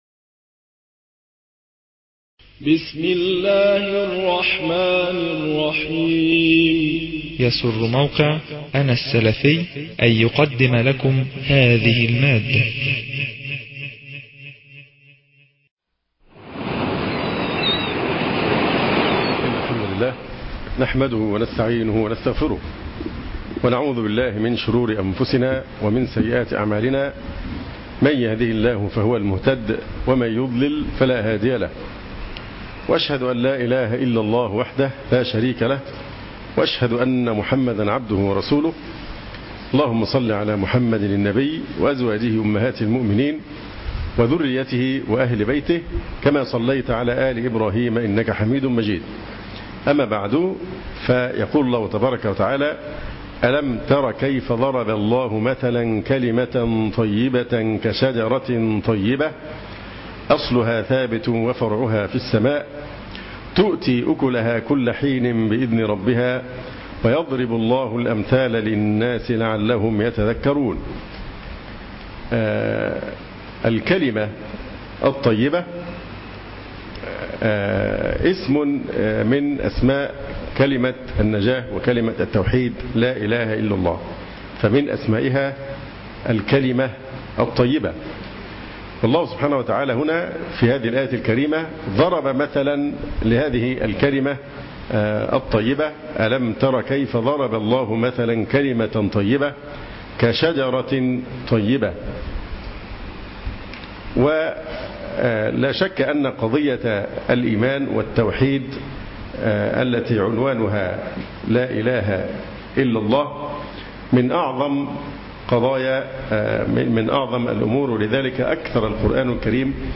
أخواتي الكـــريمـــات أقــــدم إليـــكــن درس مـــاتع للشيـــــخ